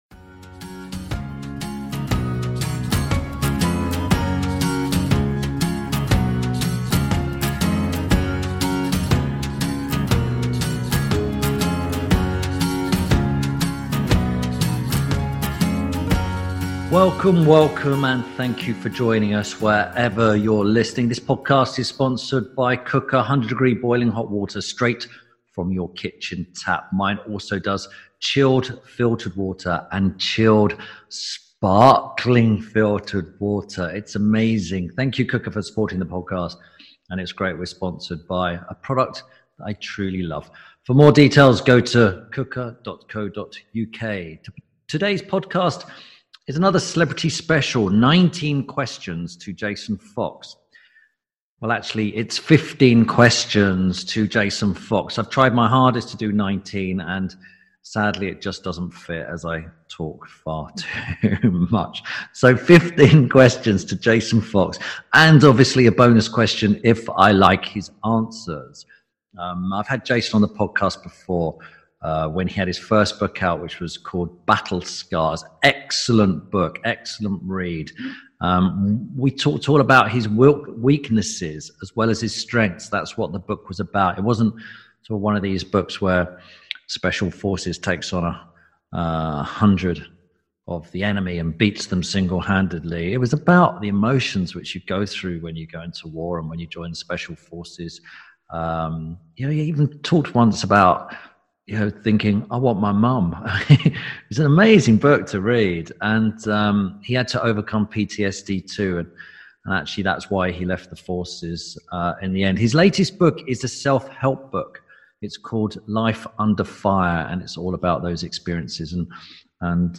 Tim Lovejoy asks Ex Special Forces Jason Fox 15 questions about his life